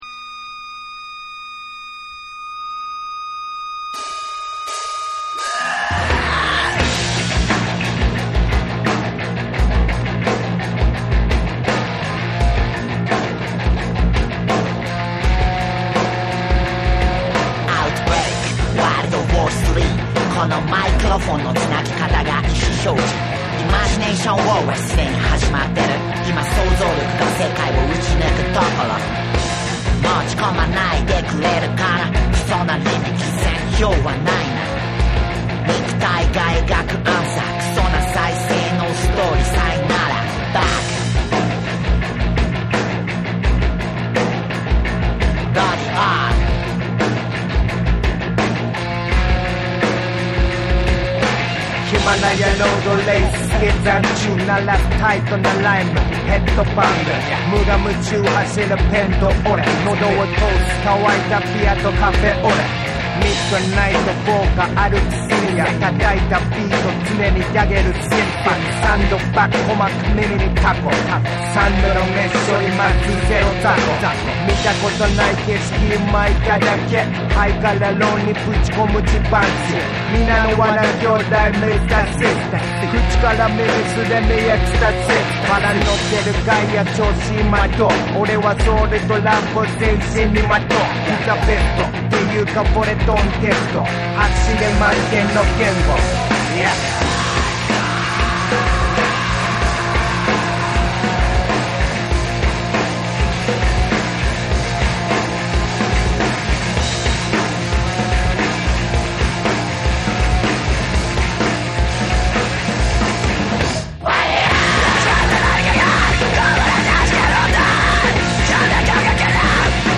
JAPANESE / NEW WAVE & ROCK